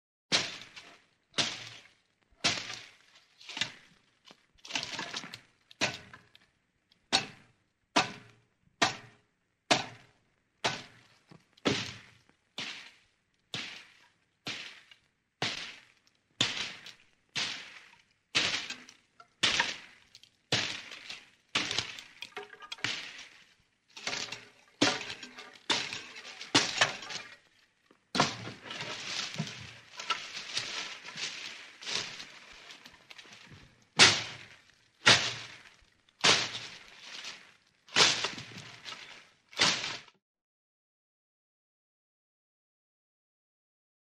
Звук топора по дереву
Звук, как будто прорубаются сквозь кусты или расчищают пространство